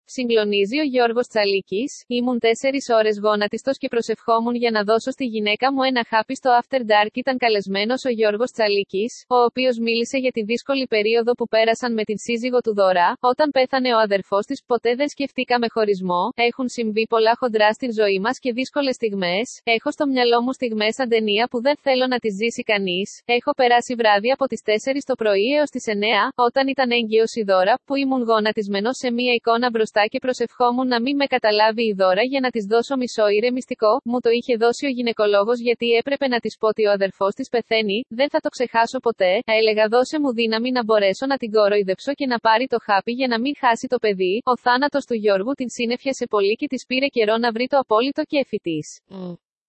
Στο After Dark ήταν καλεσμένος ο Γιώργος Τσαλίκης